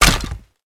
hurt4.ogg